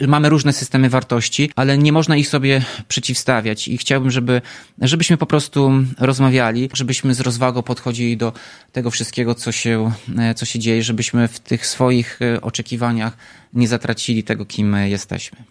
Mówił Tomasz Andrukiewicz.